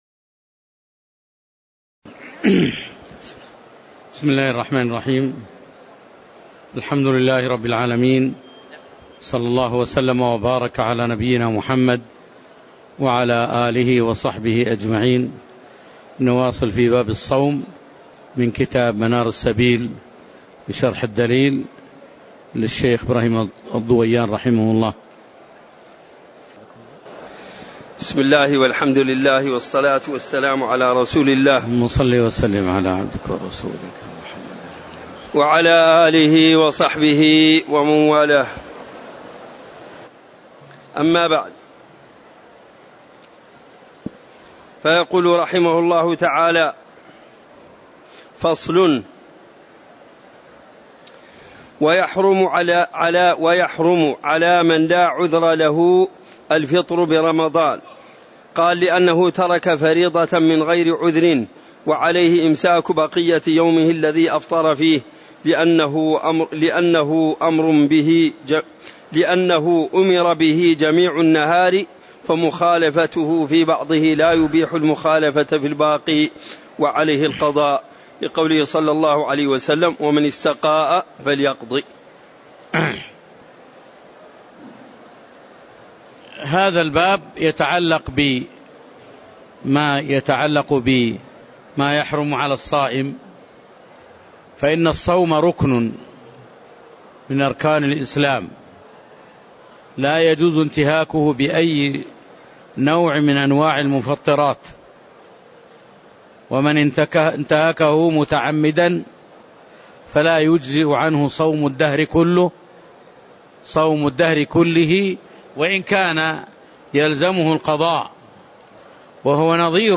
تاريخ النشر ٨ رمضان ١٤٣٨ هـ المكان: المسجد النبوي الشيخ